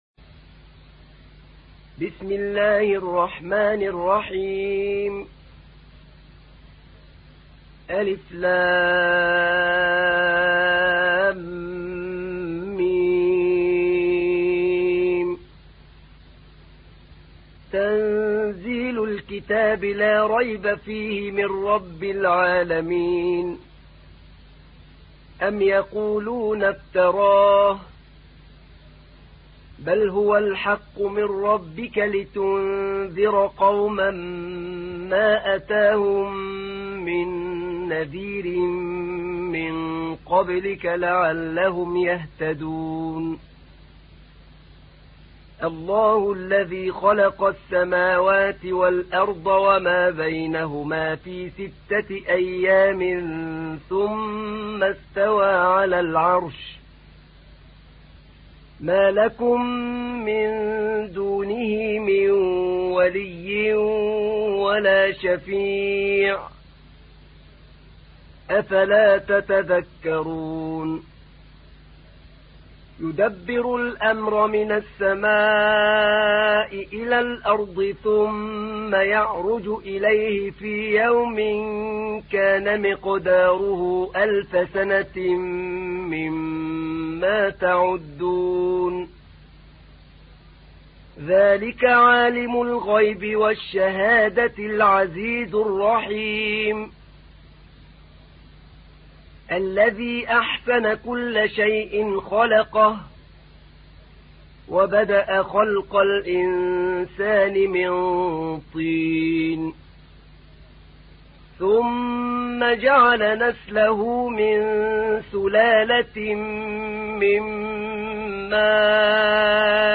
تحميل : 32. سورة السجدة / القارئ أحمد نعينع / القرآن الكريم / موقع يا حسين